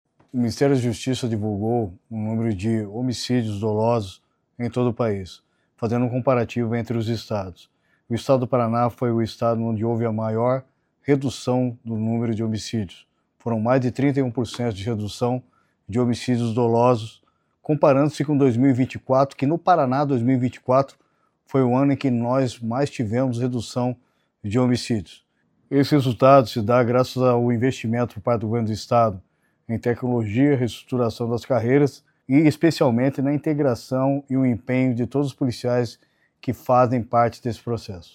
Sonora do secretário da Segurança Pública, Hudson Leôncio Teixeira, sobre o Paraná ser o estado com maior redução nos índices de homicídios dolosos no País